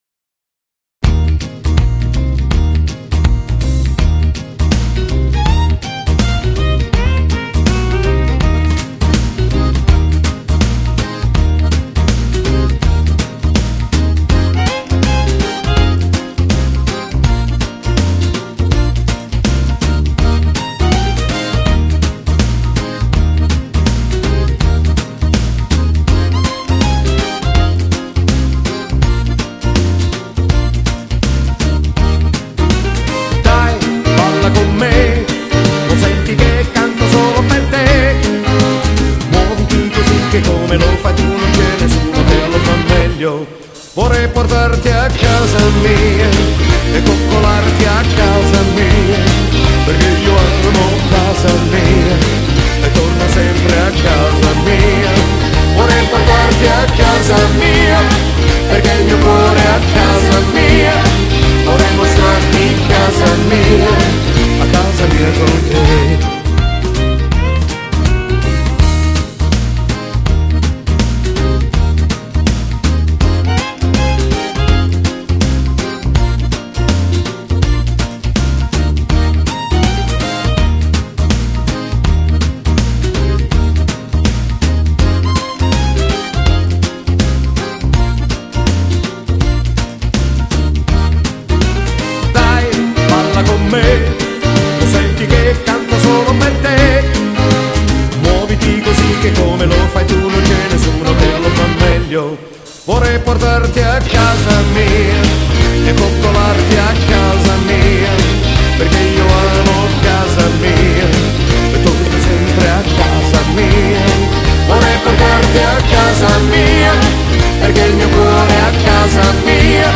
Genere: Rock and roll